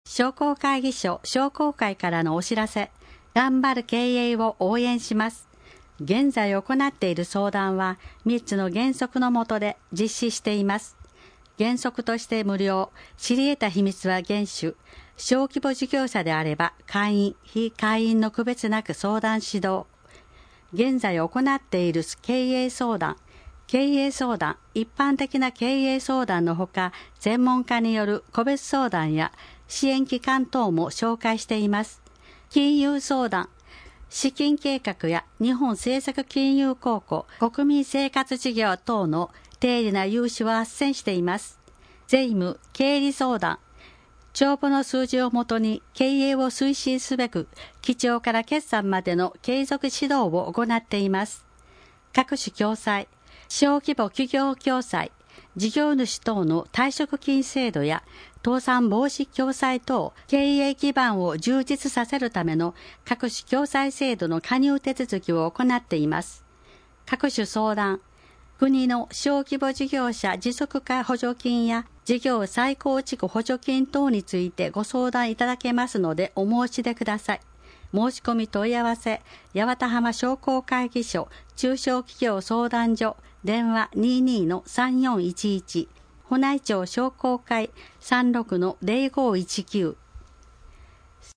目の不自由な方にも市政の動きを知っていただくため、「広報やわたはま」をカセットテープやCDに吹き込み、希望者に配布しています。
なお「声の広報」は、朗読ボランティアどんぐりの協力によって作成しています。